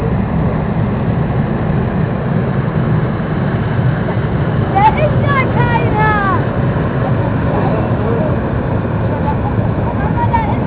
Grenzverkehr   Hafeneinfahrt
Hier kommt gerade die "Schleswig-Holstein" in ihren Heimathafen. 45  Minuten dauert die Überfahrt von Rødby nach Puttgarden.
faehre.wav